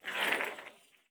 Ice Skate C.wav